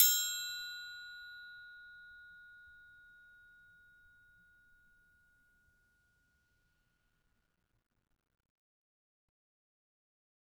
Triangle3-Hit_v2_rr2_Sum.wav